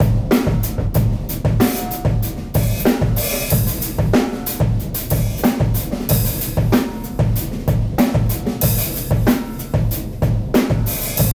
Room Mic Comparison
Late night tracking session for upcoming compilation, Set up three different stereo room fill mics.
They all sound great – the PZM for a rounder old-skool feel, the 414 or MD1 for a bit more punch!
What’s nice is that the drums don’t punch so much, leaving more of the thick and juicy room sound.